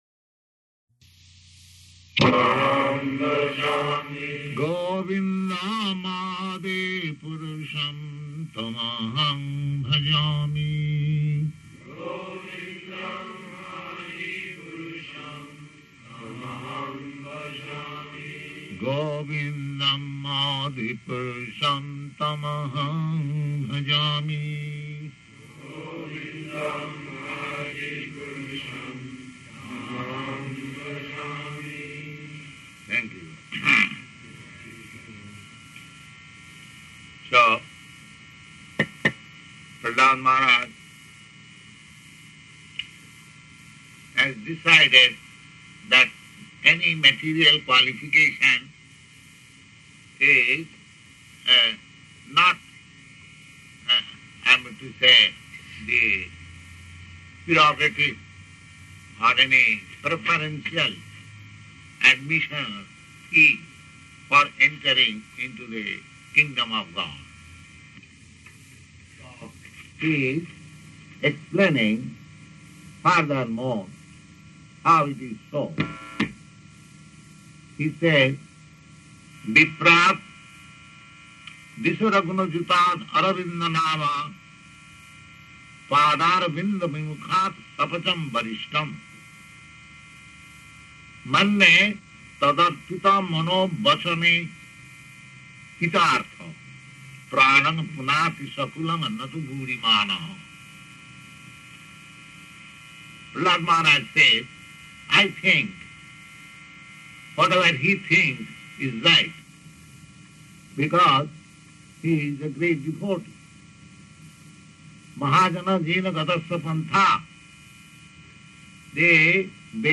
Type: Srimad-Bhagavatam
Location: Montreal